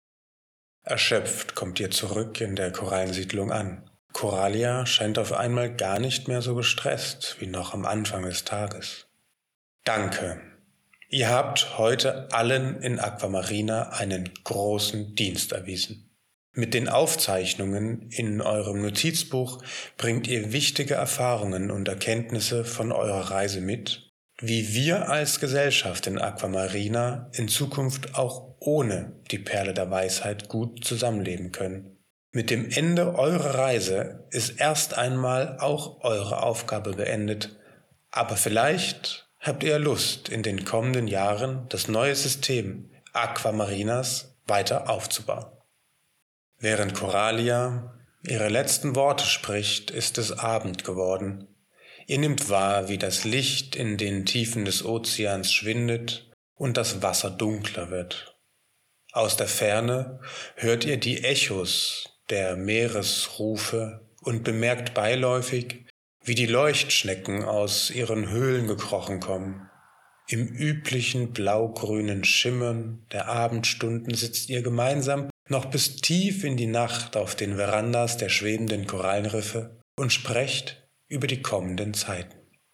Inhaltlich gibt es keinen Unterschied, es wird jedoch empfohlen das Hörspiel zu nutzen, da es die Atmosphäre der Geschichte lebhaft darstellt.